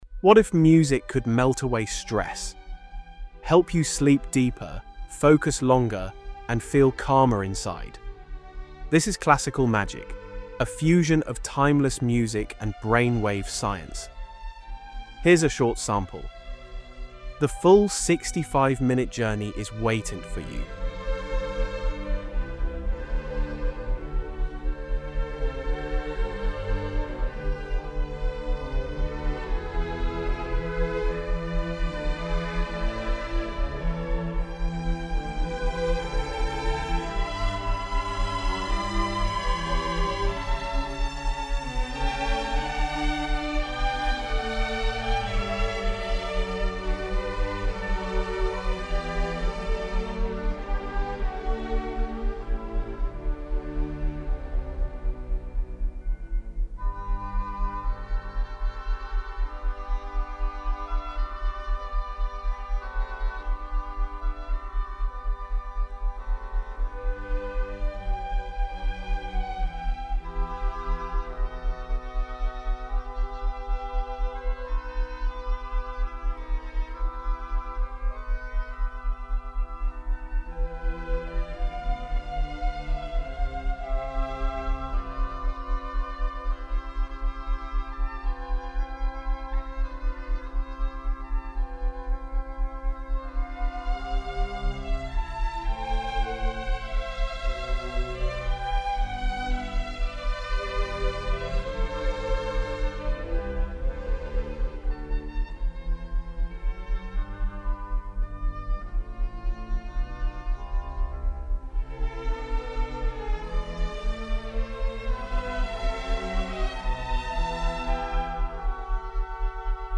background sound with foreground impact.
> You hear only the beauty of Baroque